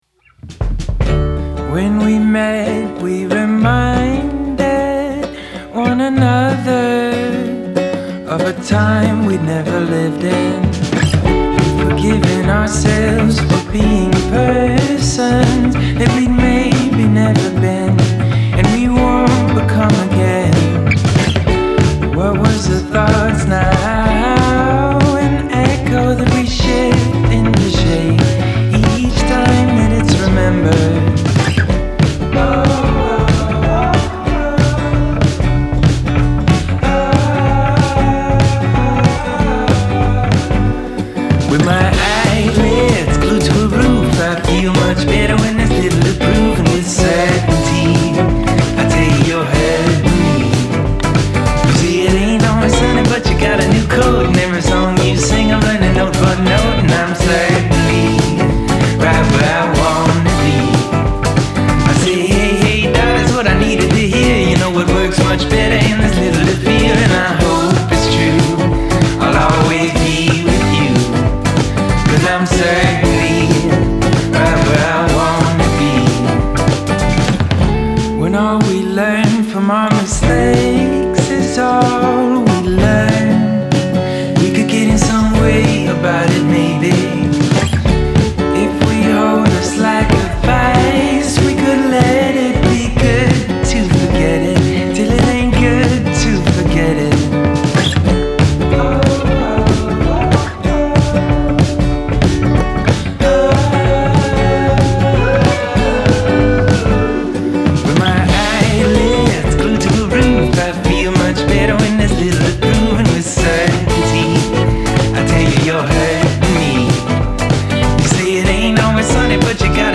great guitar work and a catchy melody